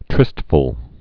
(trĭstfəl)